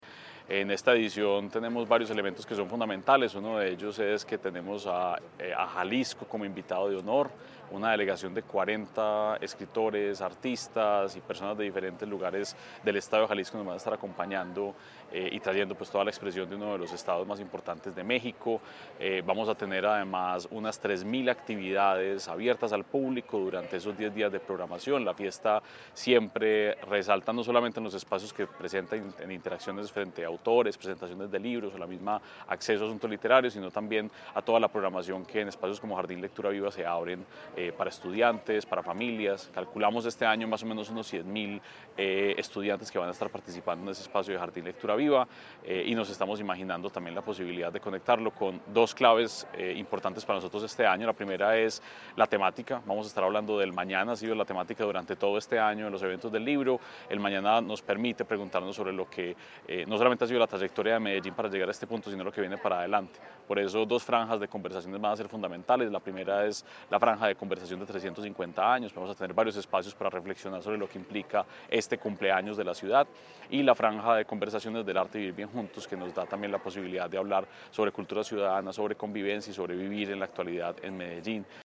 Declaraciones secretario de Cultura Ciudadana, Santiago Silva Jaramillo Del viernes 12 al domingo 21 de septiembre, Medellín celebrará la 19.ª Fiesta del Libro y la Cultura, una edición especial en torno a la conmemoración de los 350 años de la ciudad.
Declaraciones-secretario-de-Cultura-Ciudadana-Santiago-Silva-Jaramillo-4.mp3